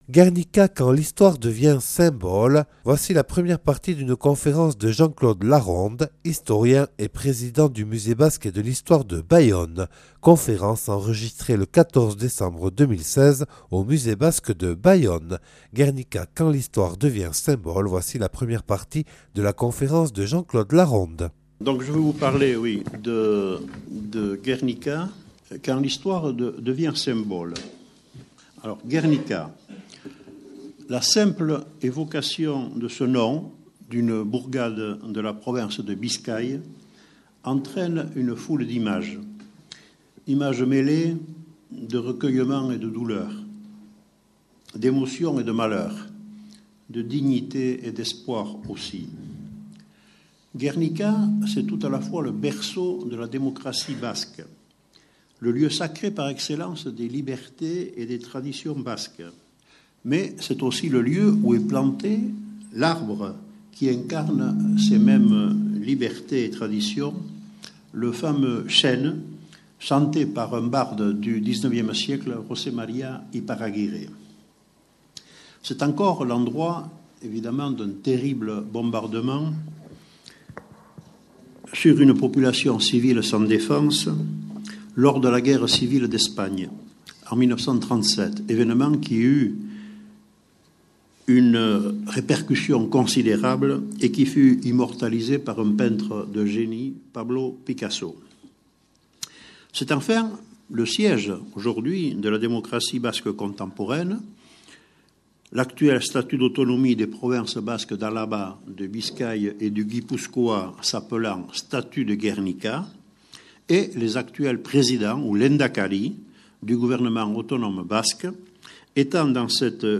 (Enregistrée le 14/12/2006 au Musée Basque et de l’histoire de Bayonne) "Gernika